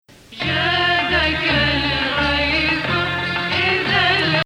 Sikah 1